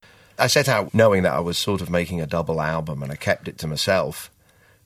The second use is for emphasis, in which case they are stressed.
In the other three examples the pronouns are used emphatically.
325.-I-kept-it-to-MYSELF-meself.mp3